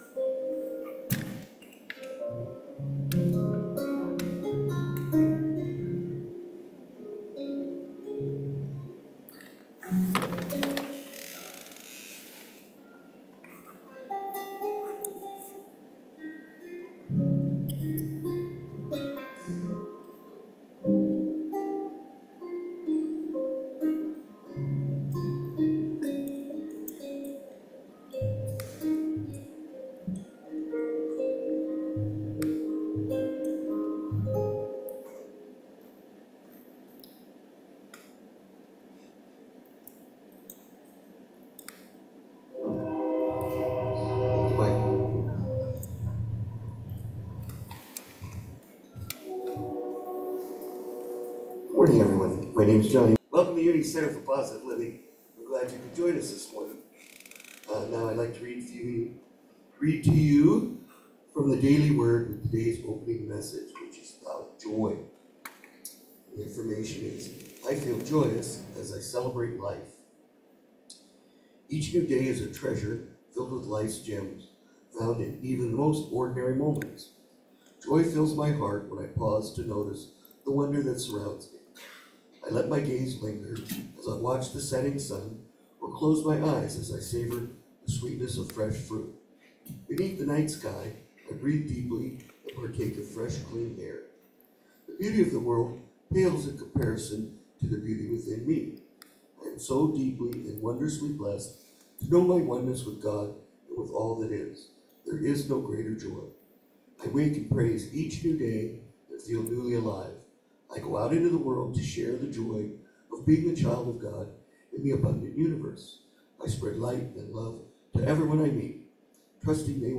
May 5, 2024 Service